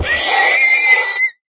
minecraft / sounds / mob / horse / death.ogg
death.ogg